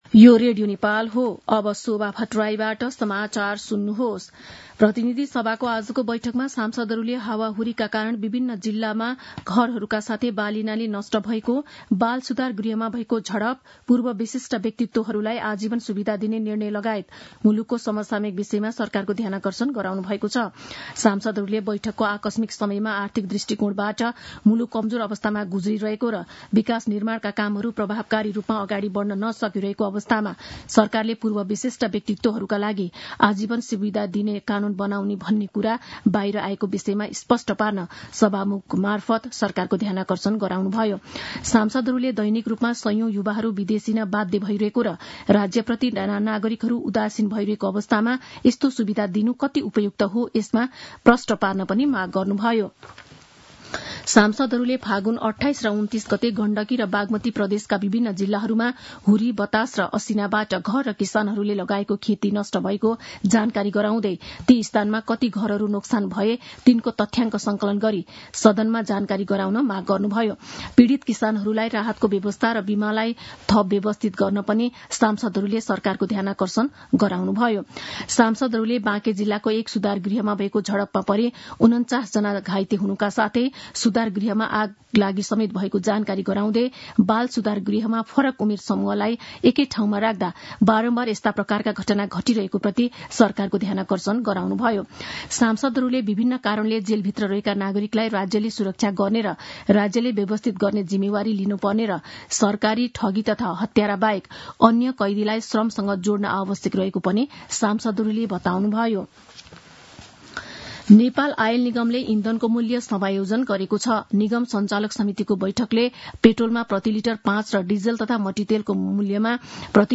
मध्यान्ह १२ बजेको नेपाली समाचार : ३ चैत , २०८१